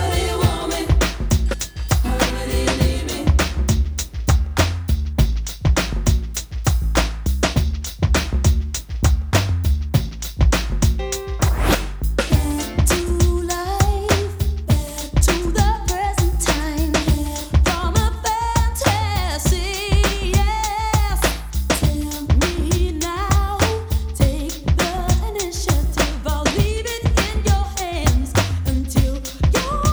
British R&B band